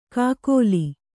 ♪ kākōli